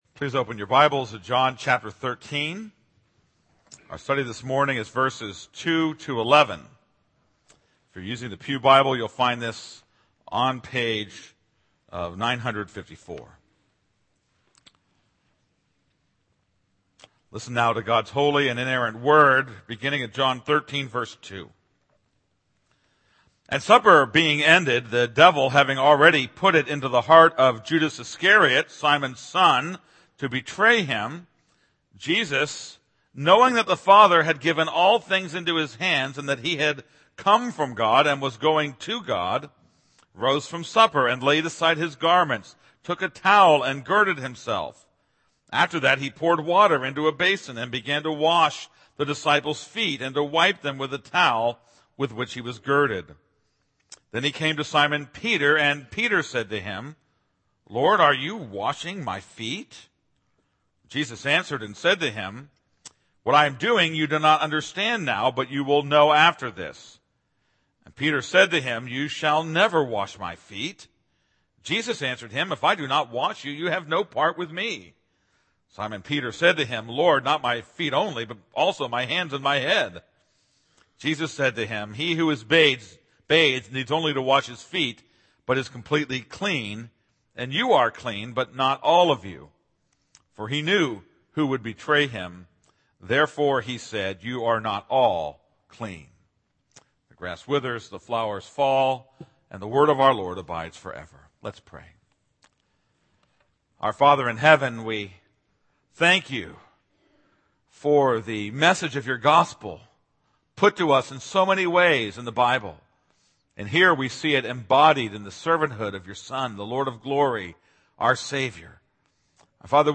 This is a sermon on John 13:2-11.